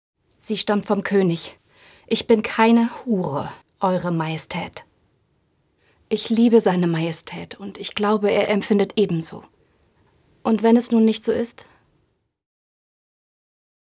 Synchron - historisch